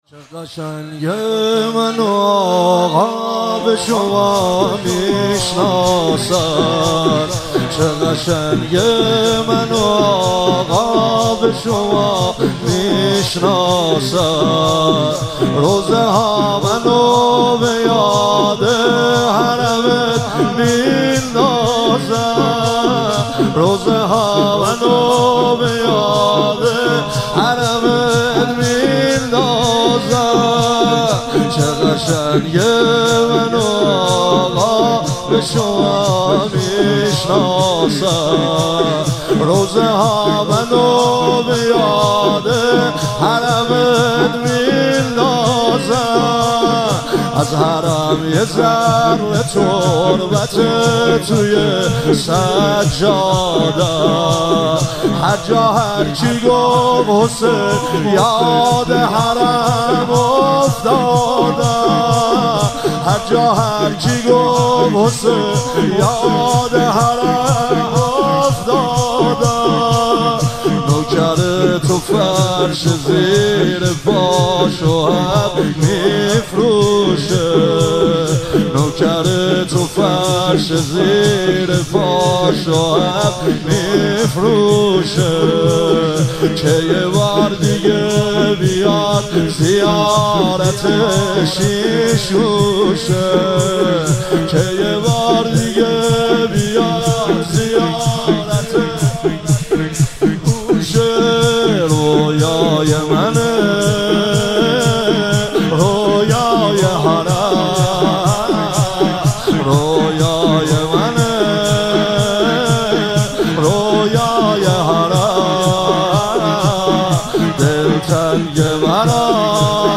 مراسم عزاداری وفات حضرت فاطمه معصومه (س)- آبان 1401
شور- چه قشنگه منو آقا به شما میشناسن